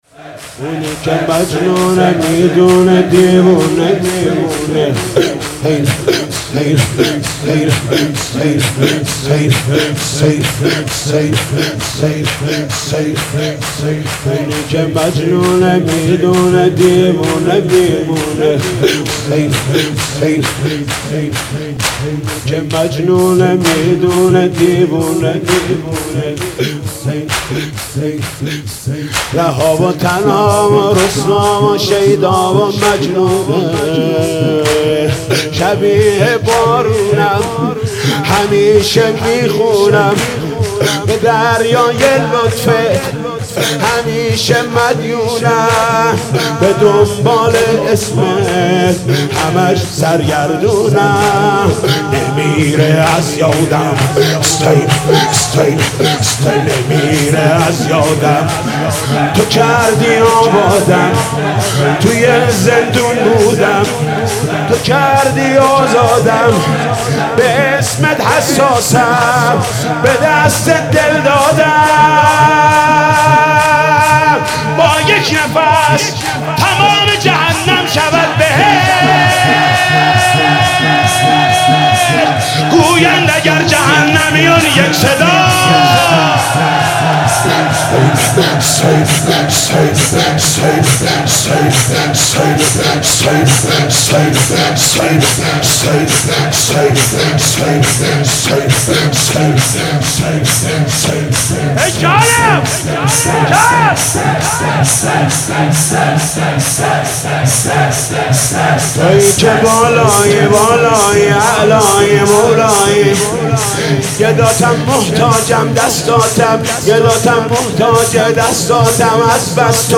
«فاطمیه 1396» شور: اونی که مجنونه میدونه دیوونه می مونه